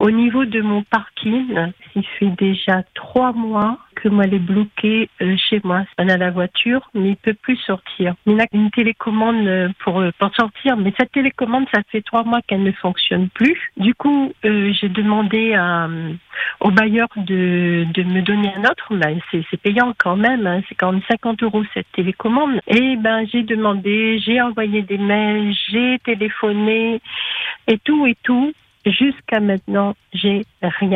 Frustrée et désabusée, elle nous confie son désarroi et l’impact que cette situation a sur sa vie quotidienne.